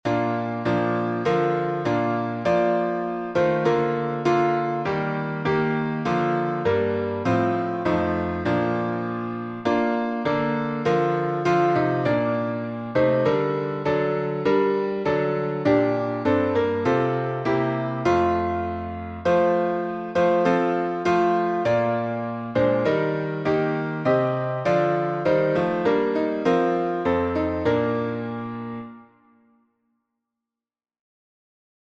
Key signature: B flat major (2 flats) Time signature: 4/4